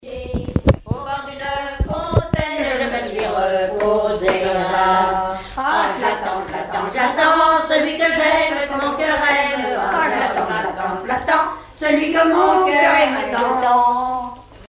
enregistré dans le dernier café d'Honfleur où il n'y avait pas de touristes
Genre laisse